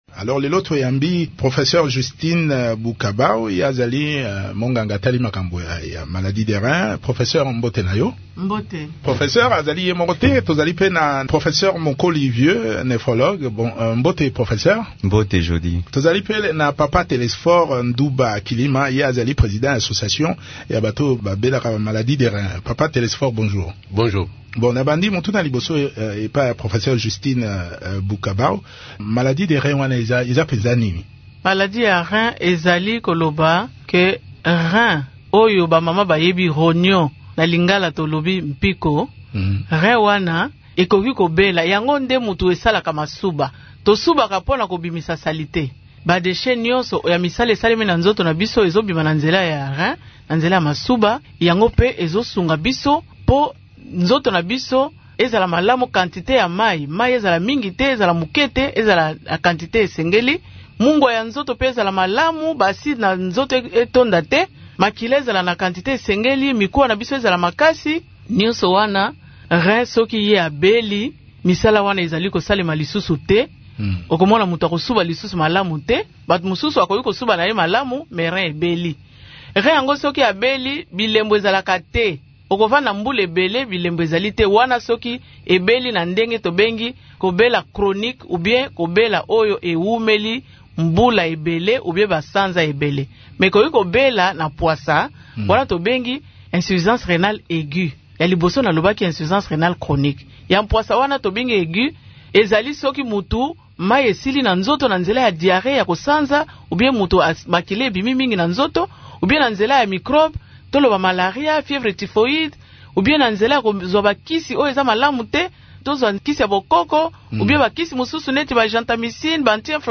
minganga babale bokono ya reins